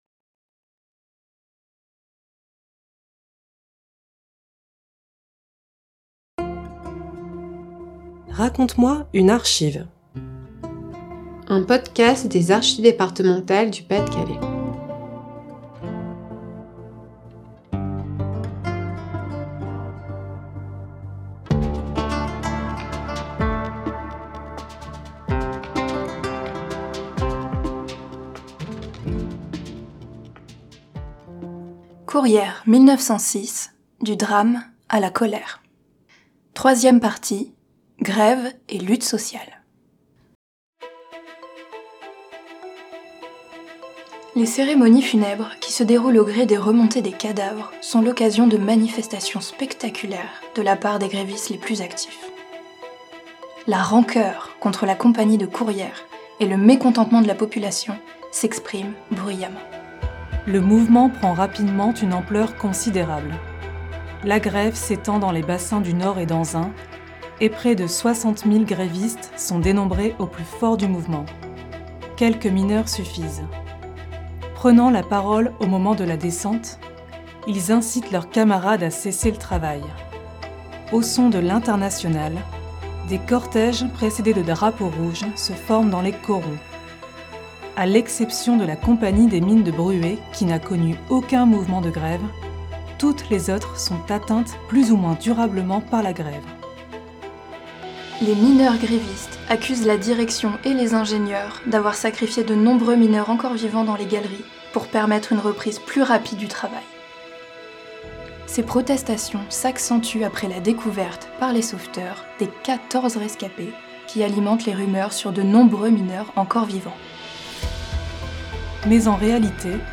Description [Narratrices] Les cérémonies funèbres qui se déroulent au gré des remontées des cadavres, sont l'occasion de manifestations spectaculaires de la part des grévistes les plus actifs.